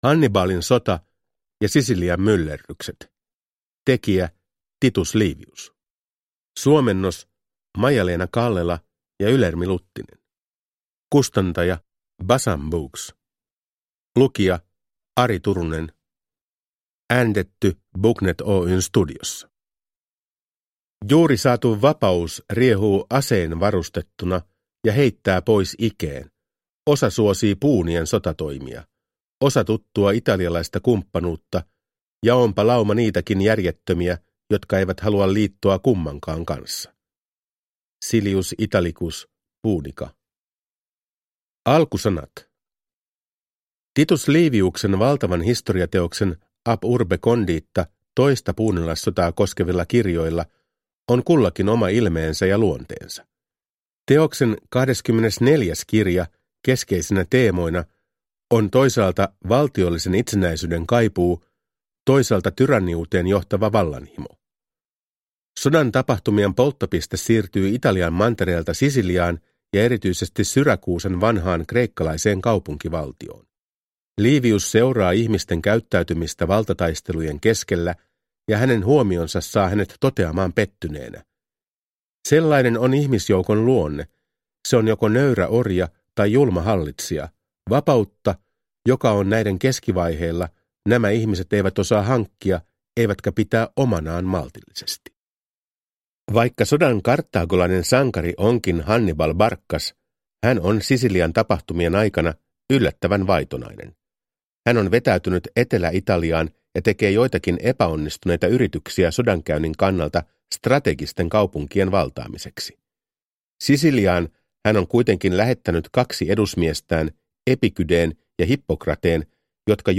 Hannibalin sota ja Sisilian myllerrykset – Ljudbok